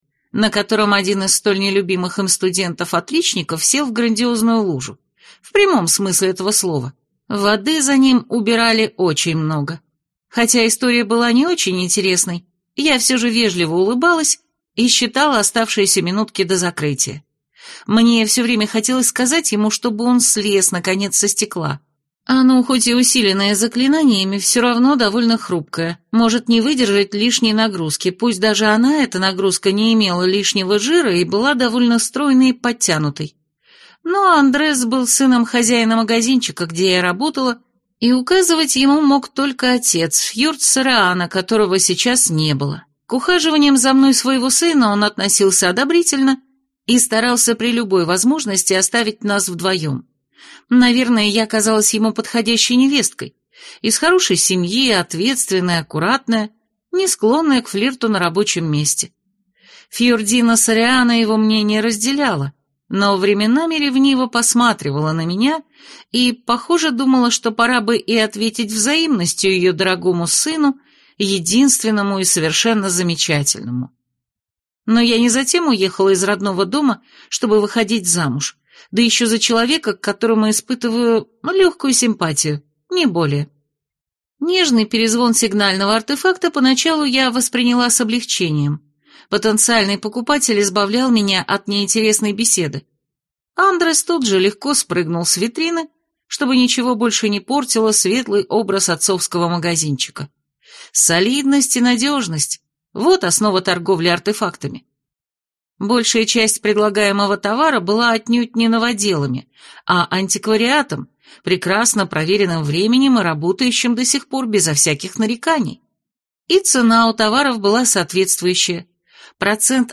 Аудиокнига Скромная семейная свадьба | Библиотека аудиокниг